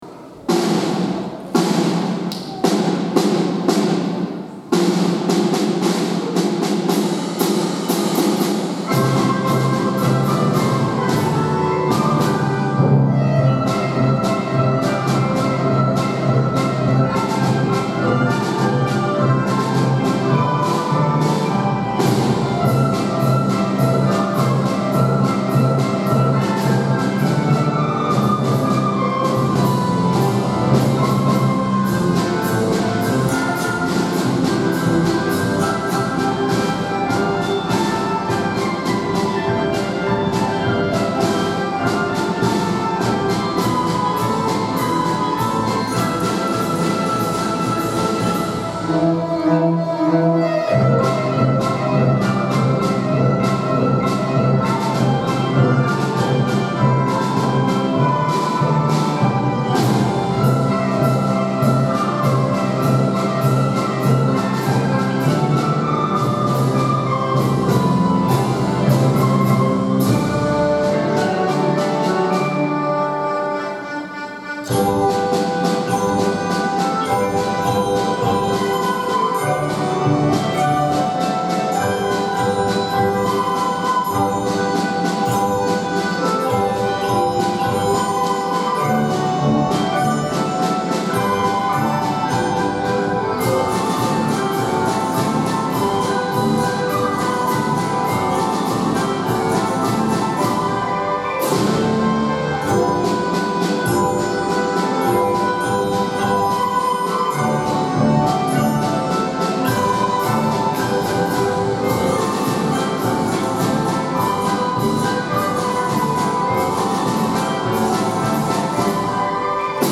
大空ありがとうコンサート
ラデツキー行進曲」４・５・６年合奏です。
演奏が始まると、身体が動き出すようなリズムが講堂中を包み込みます♪
演奏しているみんなだけでなく、聴いているみんなもリズムに合わせて体を揺らしながら音楽を楽しんでいました。
演奏が終わると、会場から自然とアンコールが声が沸き上がりました！